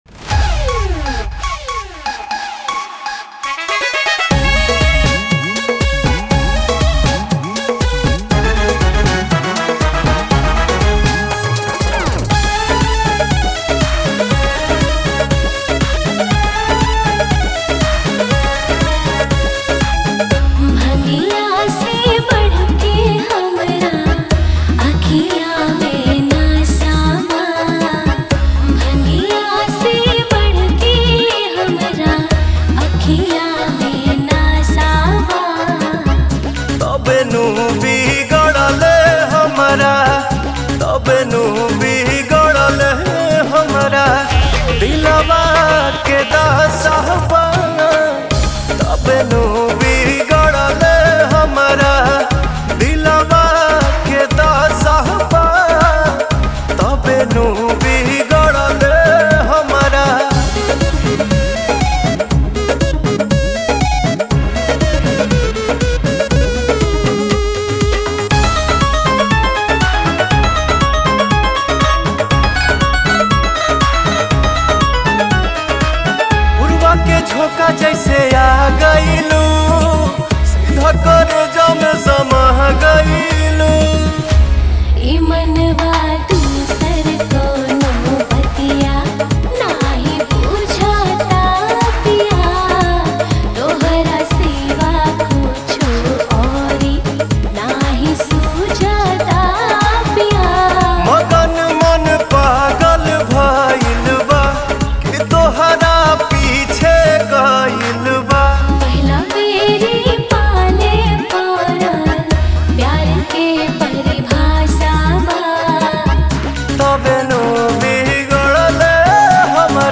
Bhojpuri Song